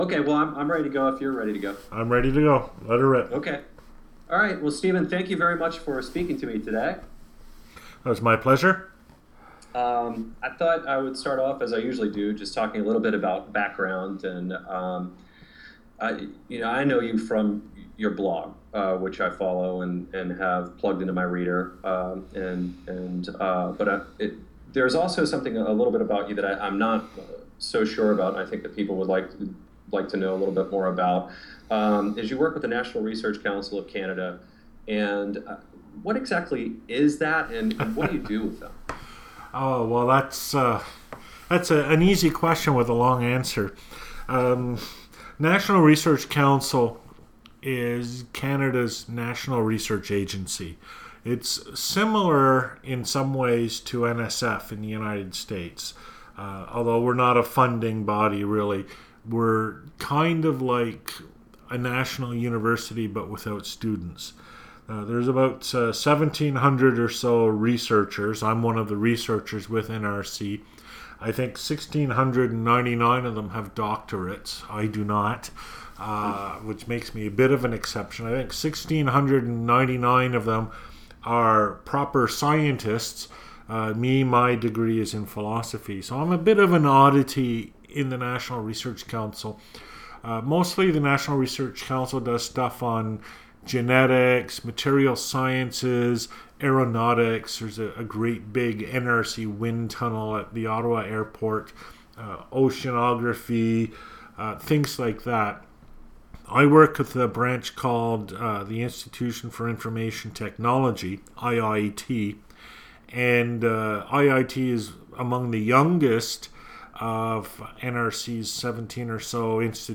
Interview for the Intellium blog. What I do with the NRC, and what the NRC does. I also talk at some length about my presentation audio and video recordings.